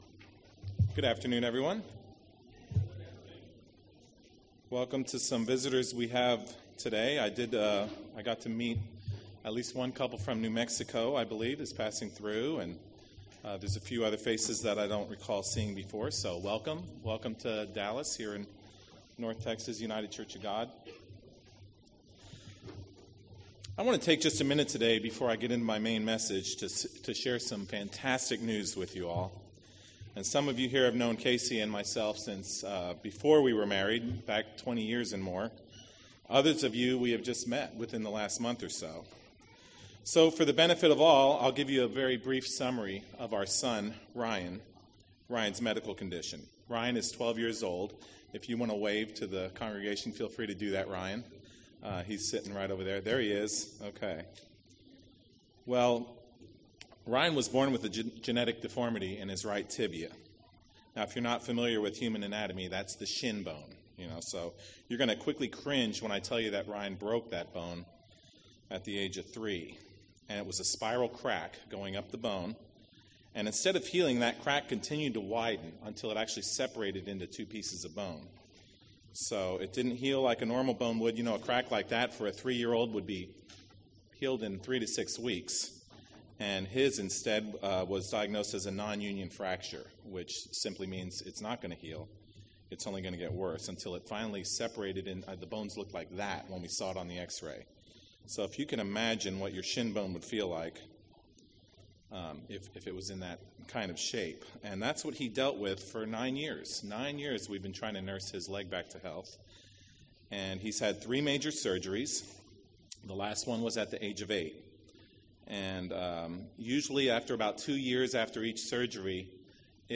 Sermons
Given in Dallas, TX